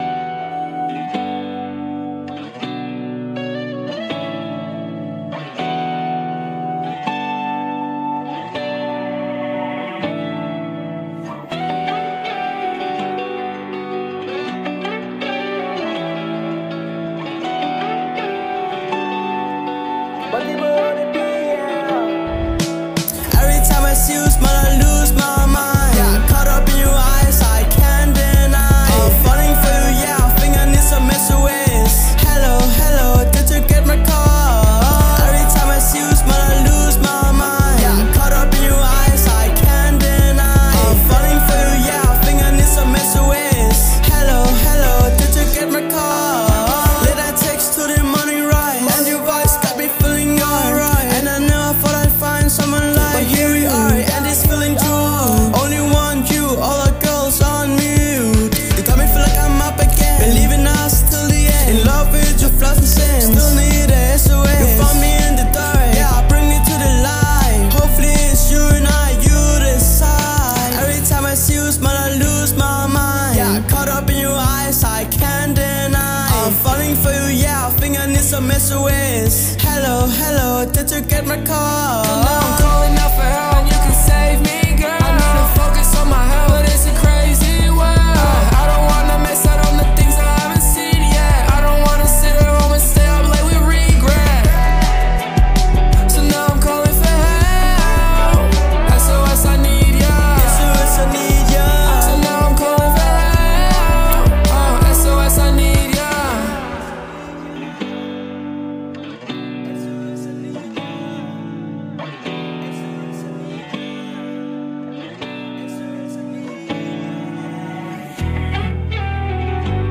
Solo Fra 550 Op til 45 minutter Upcoming artist med energi Upcoming artist med stærk energi og catchy tracks.